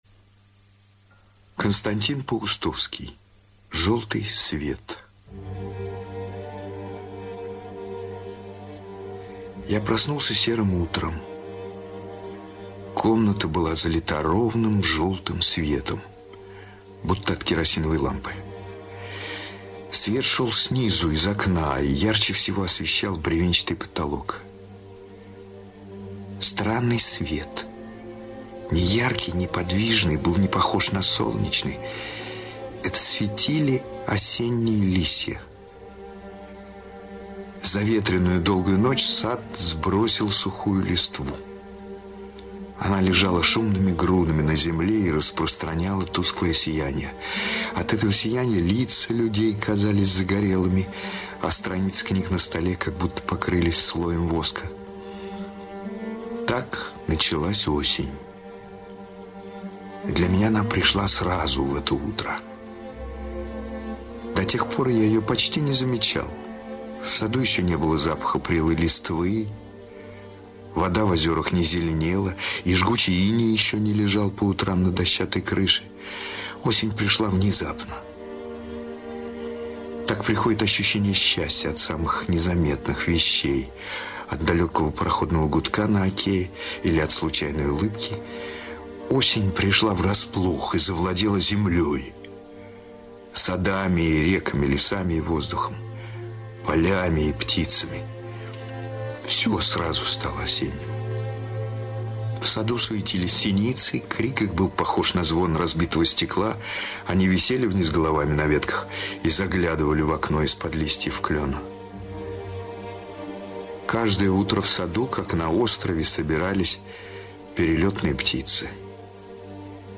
Желтый свет - аудио рассказ Паустовского - слушать онлайн